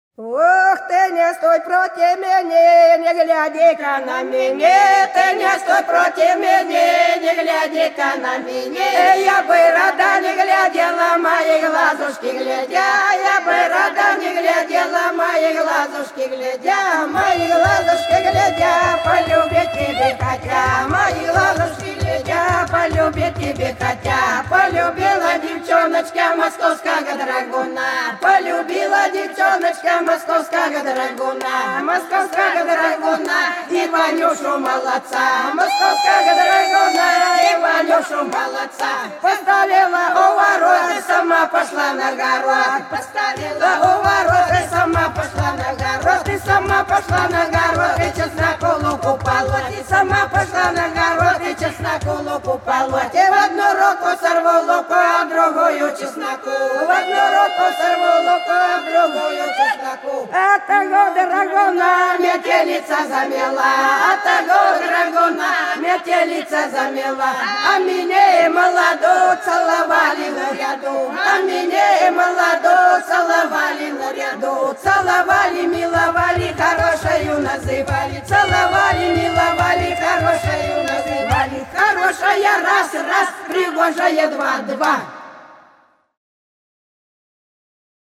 Хороша наша деревня Ты не стой против мене - плясовая (с. Иловка)
04_Ты_не_стой_против_мене_-_плясовая.mp3